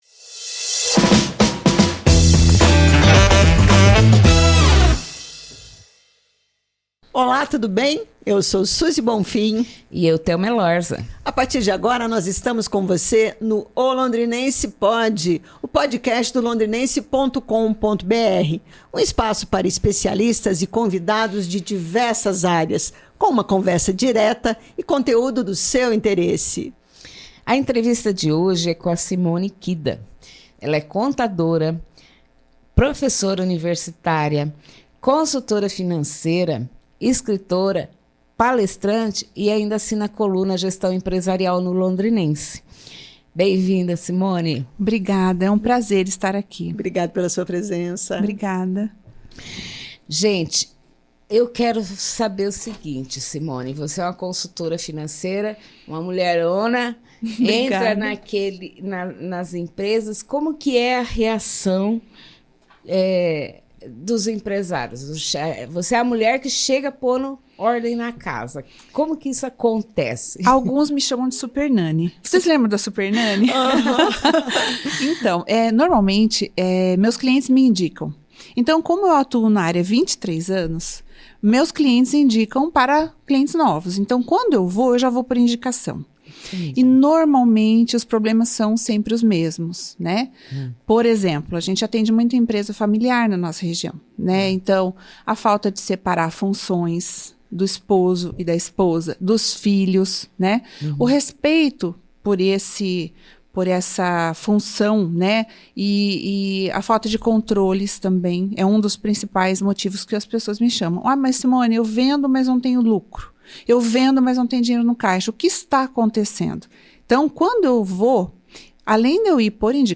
A entrevistada fala sobre os principais problemas que afligem empresas em todos os segmentos e sobre o lançamento do livro Mulheres restaurando Mulheres.